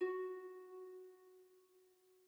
harp1_9.ogg